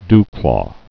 (dklô, dy-)